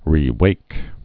(rē-wāk)